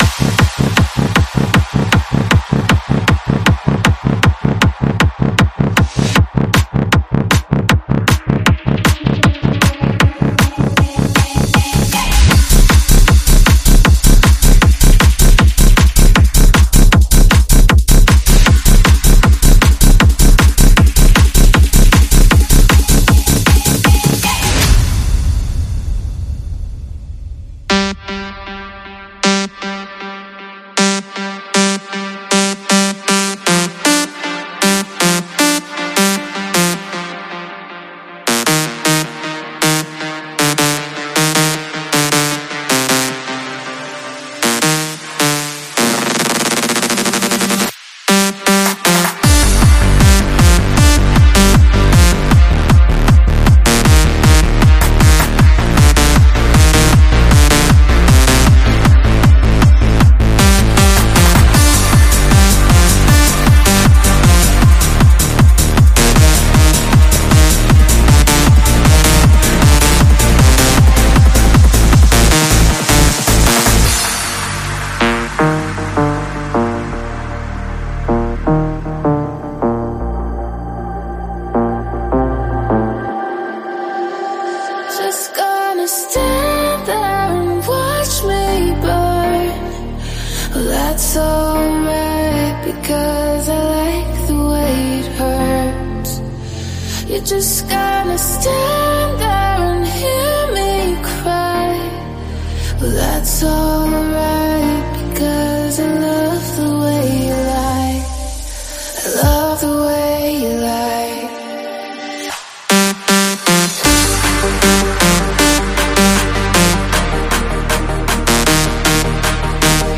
试听文件为低音质，下载后为无水印高音质文件 M币 10 超级会员 M币 5 购买下载 您当前未登录！